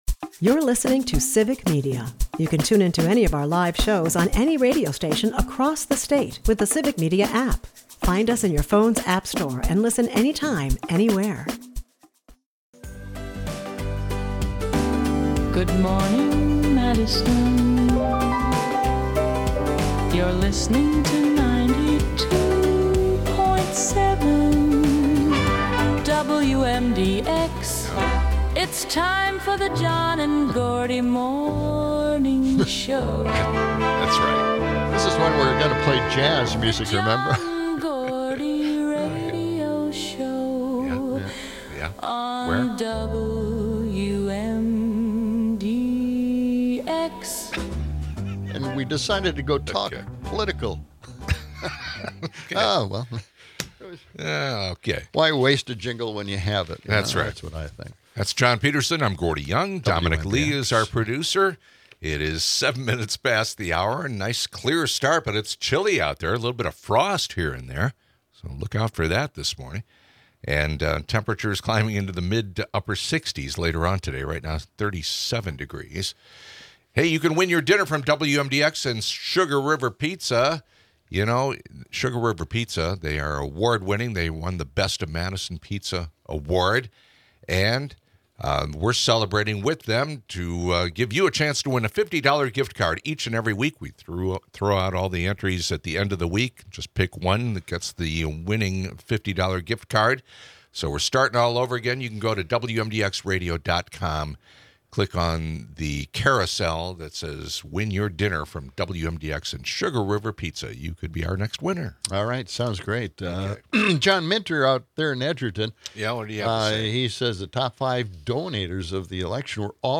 The hosts discuss political strategies, emphasizing the importance of energizing the base and expanding it to effect change.
Former Mayor Paul Soglin shares insights on how protests invigorate movements and the significance of moderate candidates in swing districts.